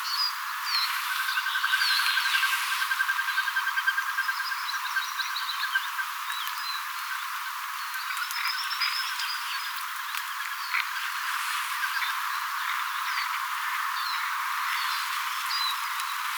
koirastelkkä lentää keväällä
koirastelkka_lentaa_kevaalla.mp3